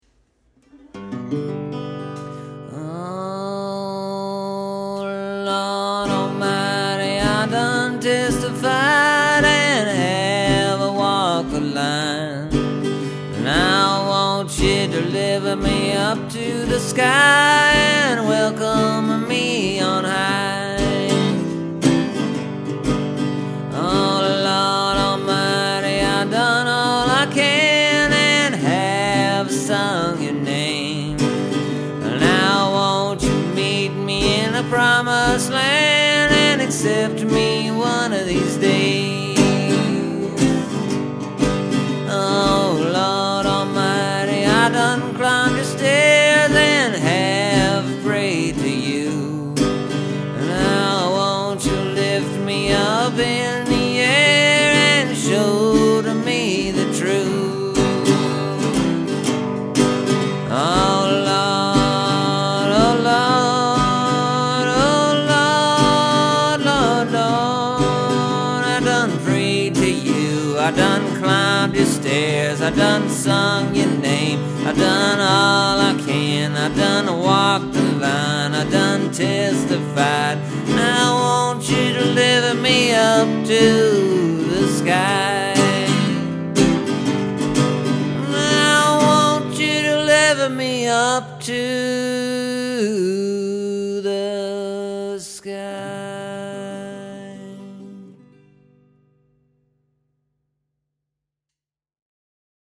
I rearranged the word order and the musical accompaniment a tad.
I’d say it’s more traditional Gospel tune and the original is more like a field howl.